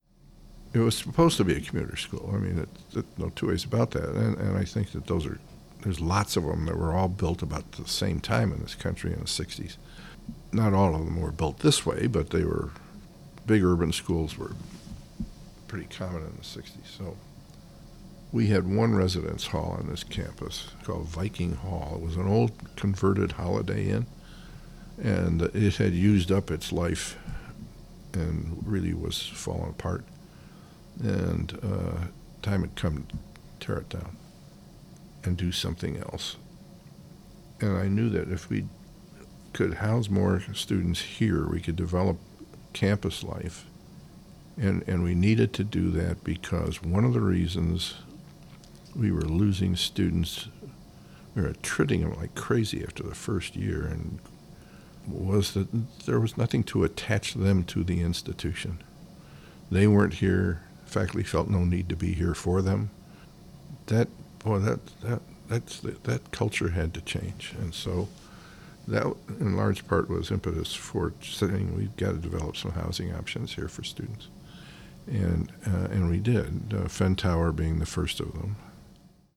Former CSU President Michael Schwartz describes why steps were taken to increase housing options for students at CSU.
Cleveland Regional Oral History Collection